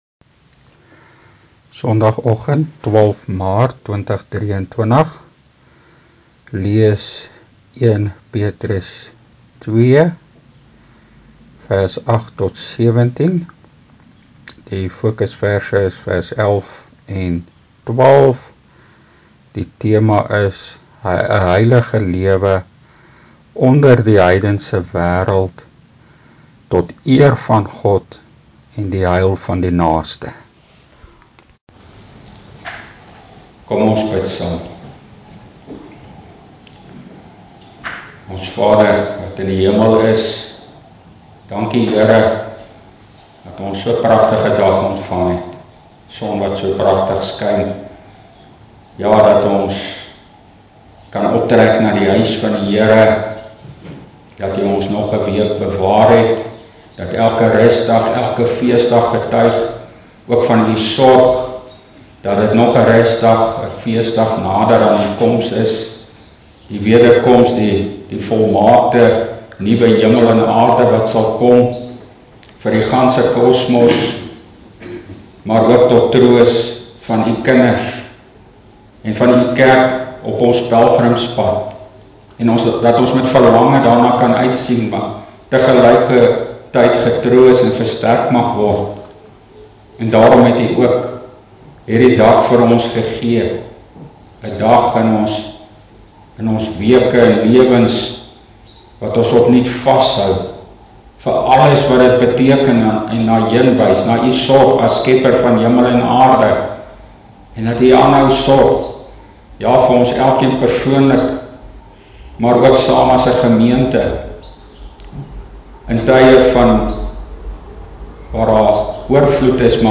Opname (GK Carletonville, 2023-03-12)